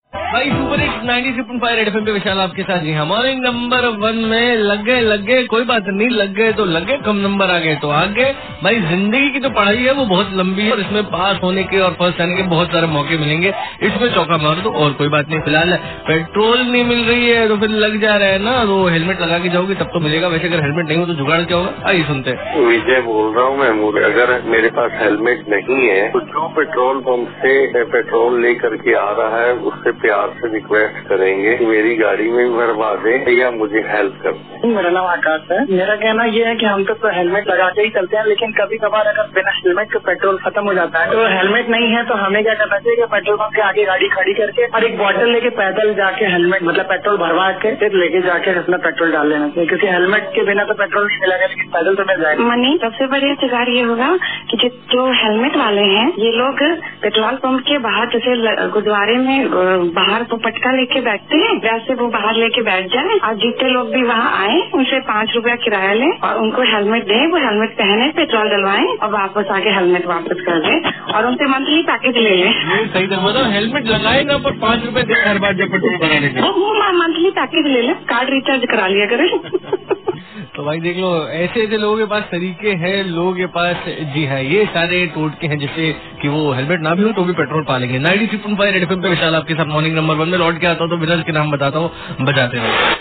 CALLER